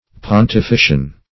Search Result for " pontifician" : The Collaborative International Dictionary of English v.0.48: Pontifician \Pon`ti*fi"cian\, a. Of or pertaining to the pontiff or pope.